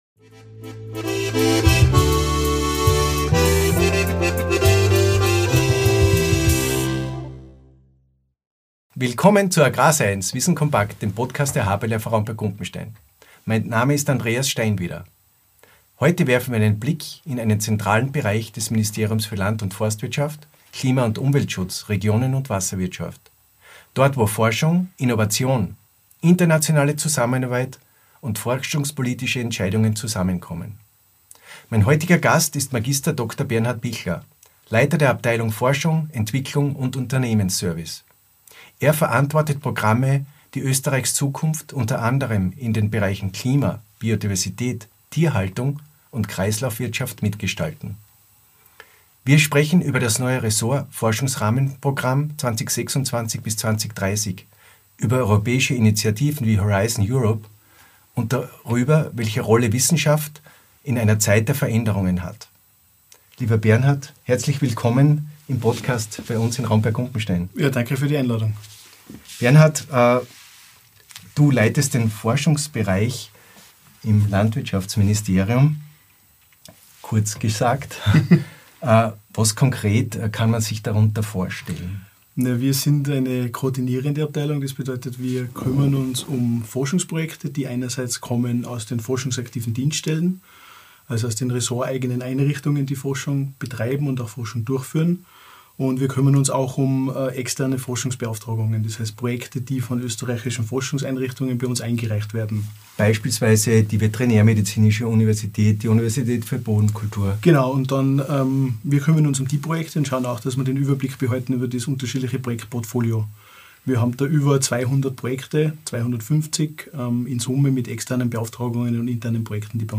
Folge 164: Forschung für die Zukunft: Das BMLUK-Forschungsprogramm 2026–2030 im Gespräch ~ AGRAR SCIENCE - Wissen kompakt Podcast